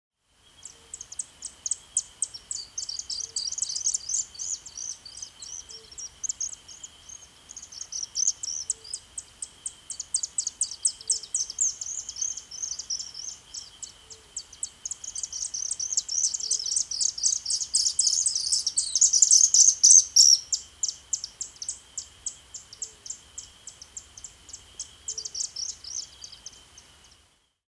На этой странице собраны звуки, издаваемые стрижами: их звонкое щебетание, крики в полёте и другие природные голоса.
Стриж - альтернативный вариант (серобрюхий иглохвост)